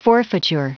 Prononciation du mot forfeiture en anglais (fichier audio)
Prononciation du mot : forfeiture